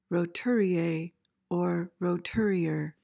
PRONUNCIATION:
(ro-TOOR-ee-ay, -uhr)